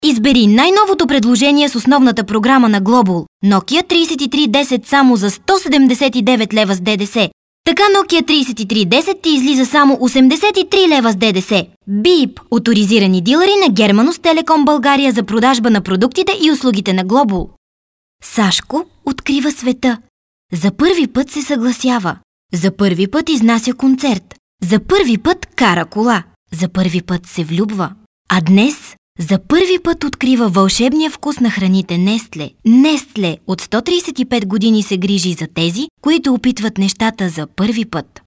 Sprecherin bulgarisch für Werbung, TV, Hörfunk, Industrie,
Sprechprobe: Werbung (Muttersprache):
Professional female voice over artist from bulgary